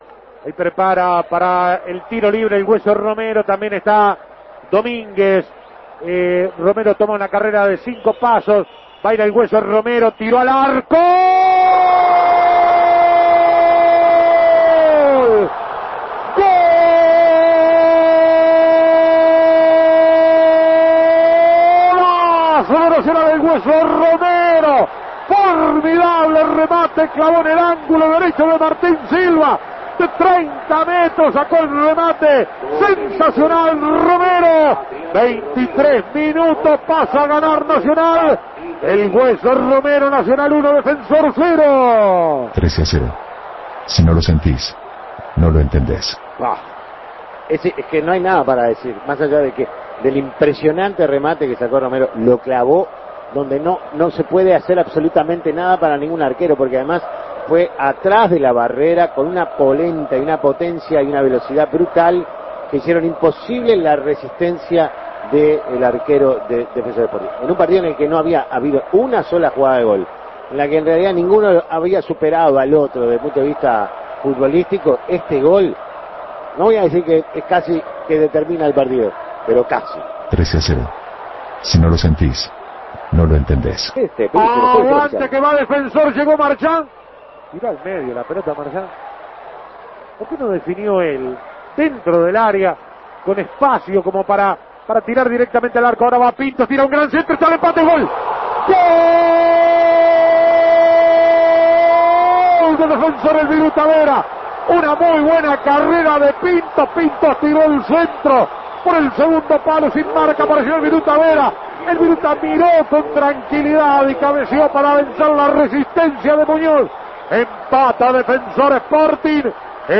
Escuche los goles del partido
Goles y comentarios